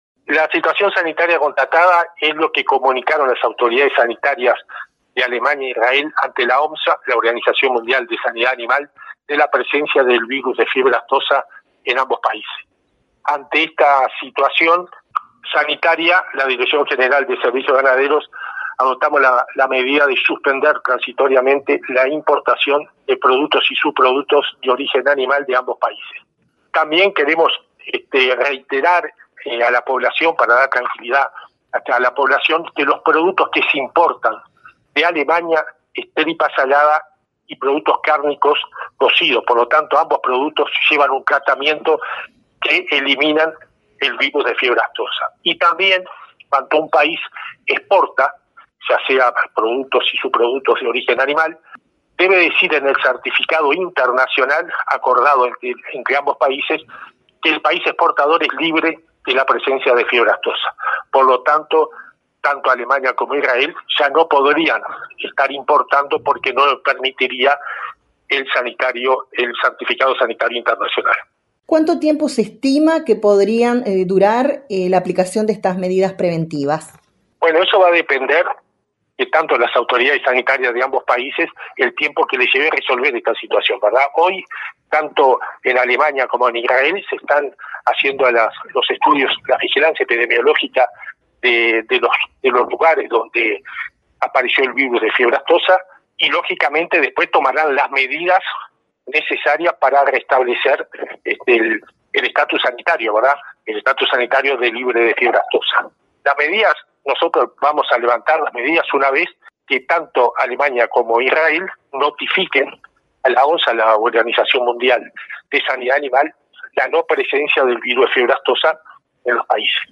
Declaraciones del director general de Servicios Ganaderos del MGAP, Diego De Freitas